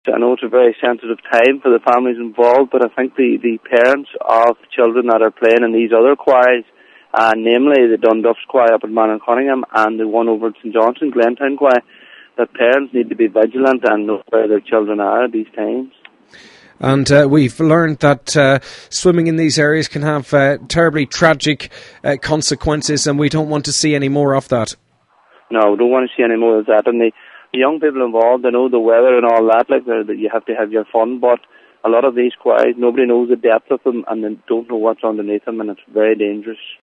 There are two quarries in east Donegal which young people have been swimming in in recent days – Local Councillor Paul Canning says the tragedy in Strabane should serve as a warning: